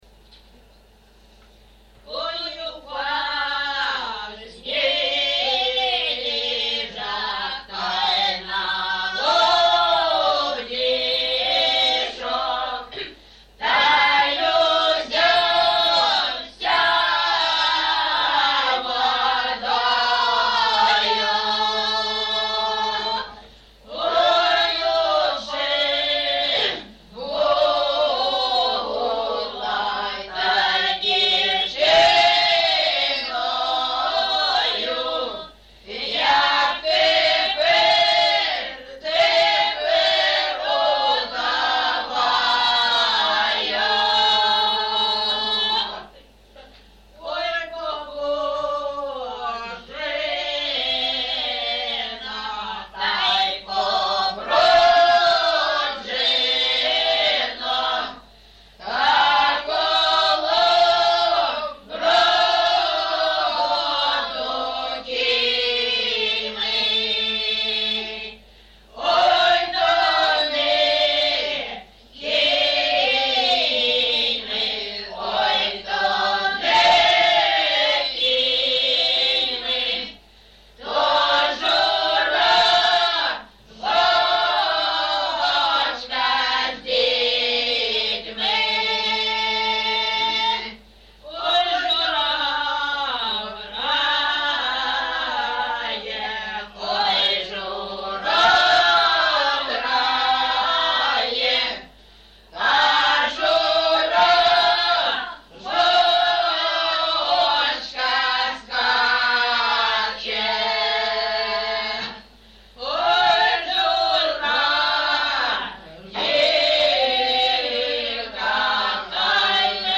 ЖанрПісні з особистого та родинного життя
МотивЖиття вдови, Нещаслива доля, Журба, туга
Місце записус-ще Щербинівка, Бахмутський район, Донецька обл., Україна, Слобожанщина